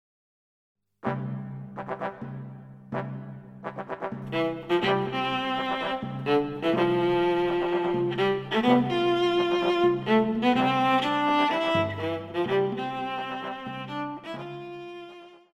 Pop
Viola
Band
Instrumental
World Music,Fusion
Only backing